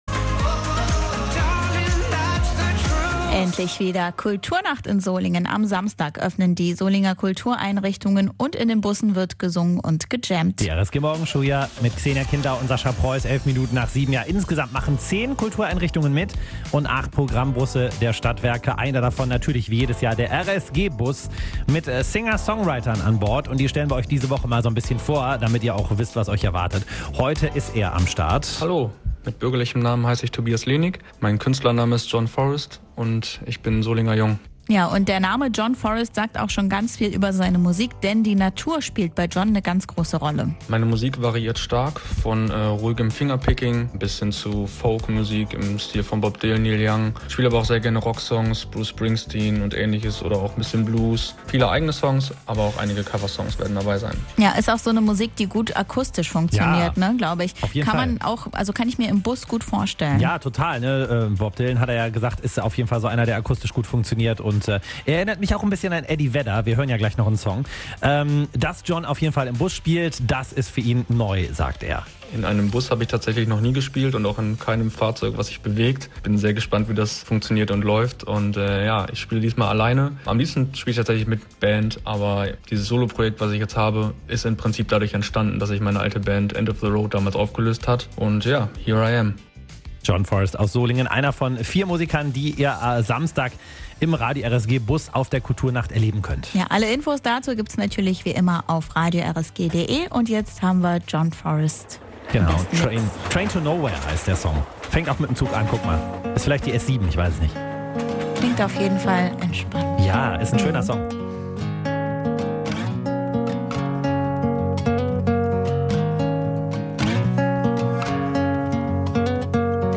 ruhigem, harmonischem Fingerpicking
Live spielt er neben seinen eigenen Liedern auch einige Coversongs seiner Idole.
Jugendliche und Erwachsene aller Altersklassen in Gitarre und Schlagzeug. https
Kultur Nacht Solingen 2022